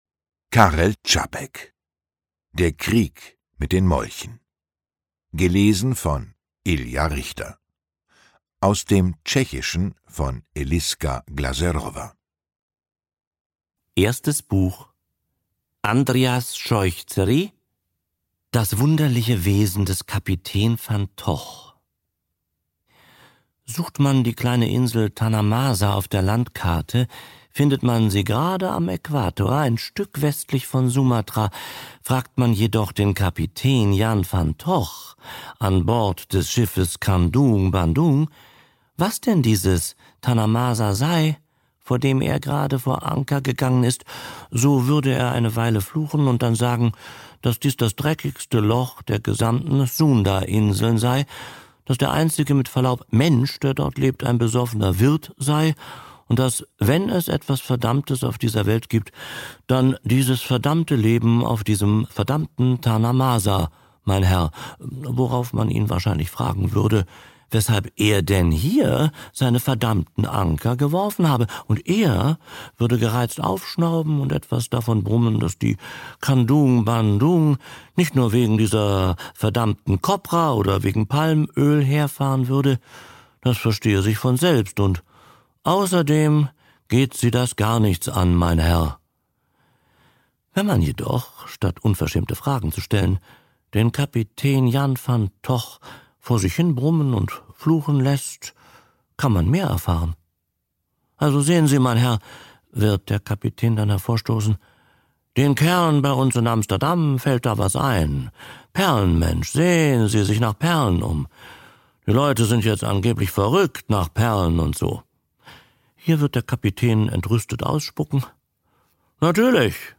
Lesung mit Ilja Richter (1 mp3-CD)
Ilja Richter (Sprecher)